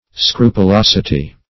Scrupulosity \Scru`pu*los"i*ty\
(skr[udd]`p[-u]*l[o^]s"[i^]*t[y^]), n. [L. scrupulositas.]